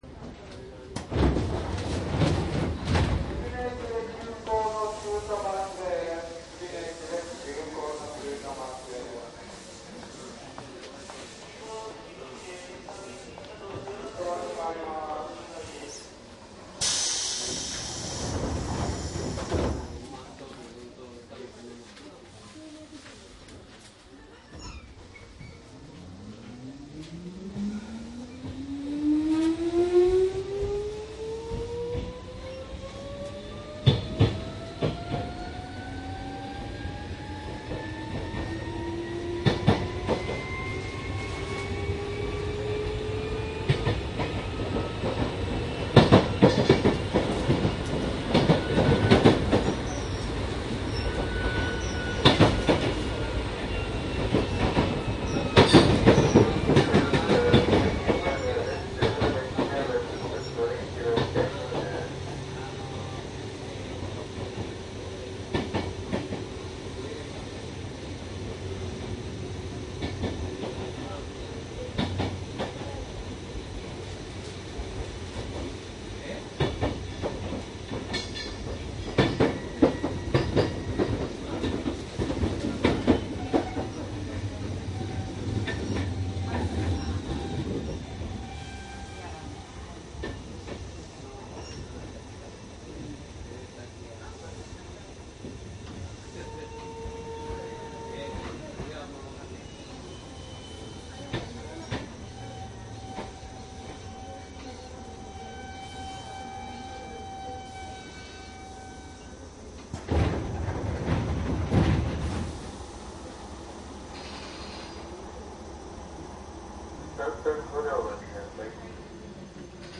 小田急2600電車 走行音CD
小田急2600系、を録音した走行音です。
いずれもマイクECM959です。DATやMDの通常SPモードで録音。
実際に乗客が居る車内で録音しています。貸切ではありませんので乗客の会話やが全くないわけではありません。